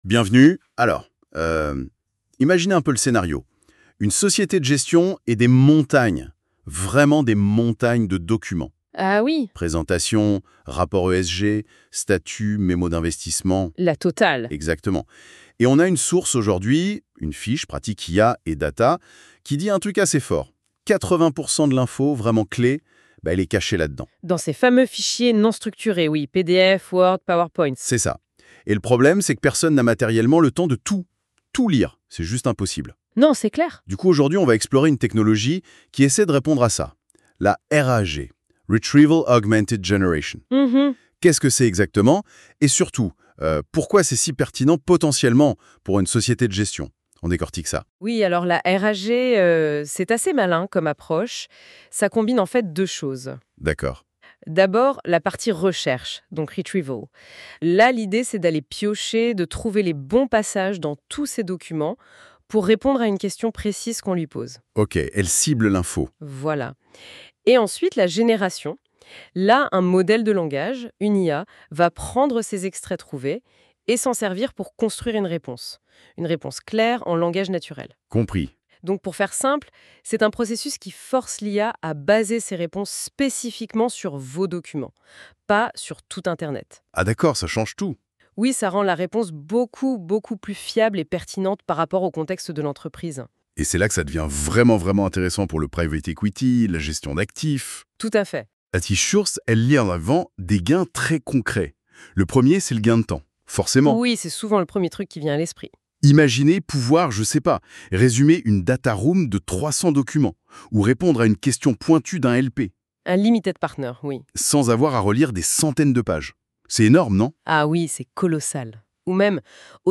NB : le podcast de BODIC est généré par l'outil d'IA NotebookLM à partir du contenu de cette fiche PDF écrite par l'équipe Bodic